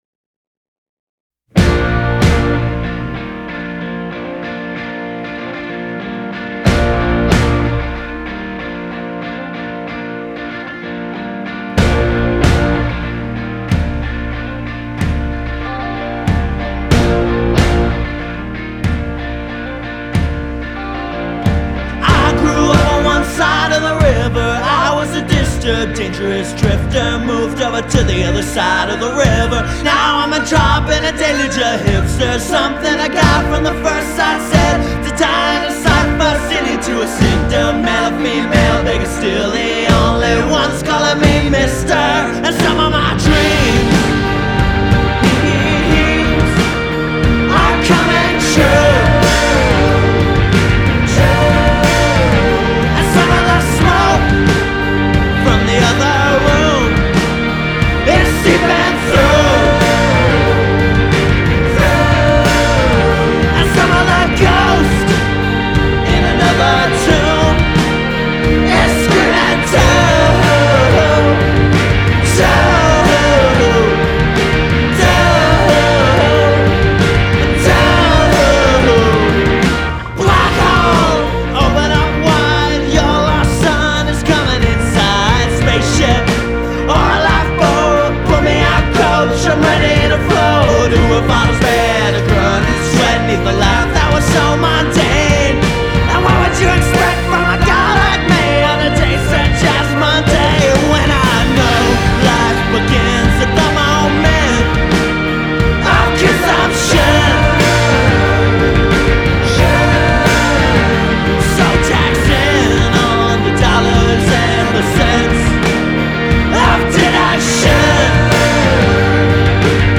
plays lead guitar and sings lead